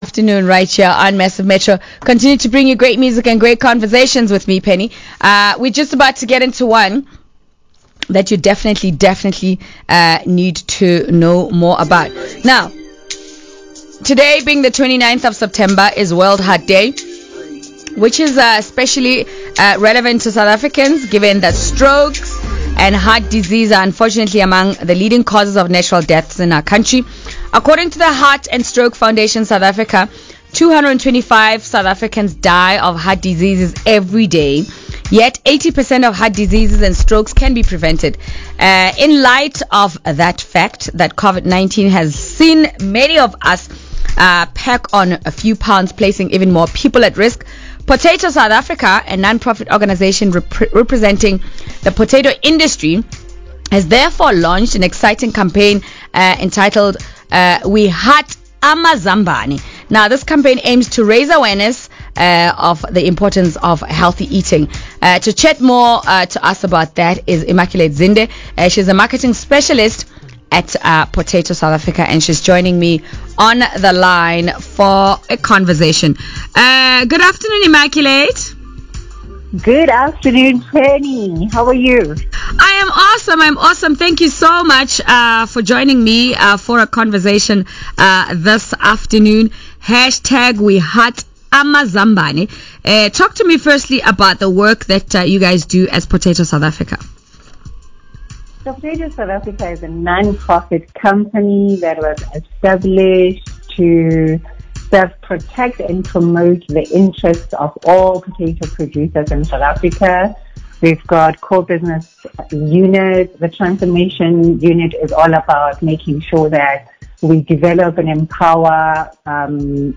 Potatoes South Africa’s Radio Interview [News, Metro FM]